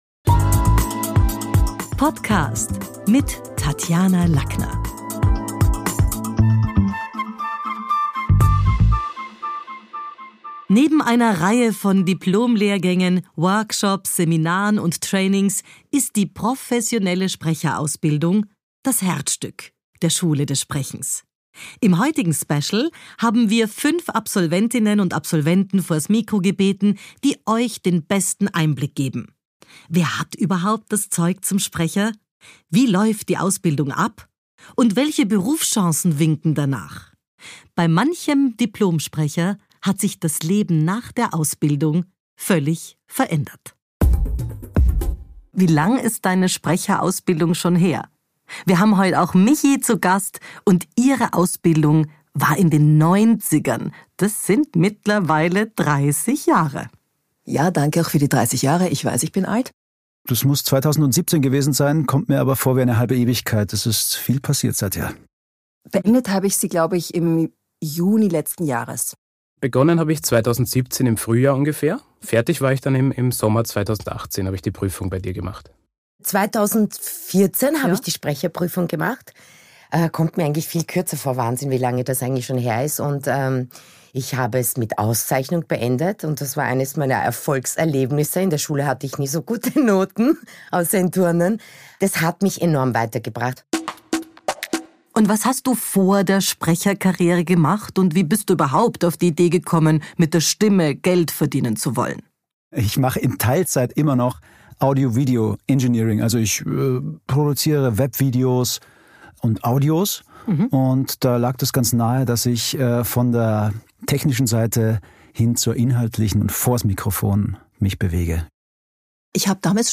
Unsere AbsolventInnen erzählen, wie man vom Nobody zum Sprecher wird und warum ein Diplom manchmal das Leben lauter macht als jede Midlife-Crisis.
Fünf unserer Alumni verraten, wie sie gelernt haben, dass ihre Stimme mächtiger sein kann als jeder Titel auf einer Visitenkarte.